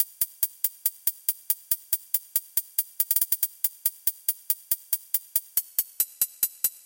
World Trap 1 Triangle Hh Only 140 Bpm
描述：三角形高帽为我的世界陷阱1鼓循环。
标签： 140 bpm Trap Loops Drum Loops 1.15 MB wav Key : Unknown
声道立体声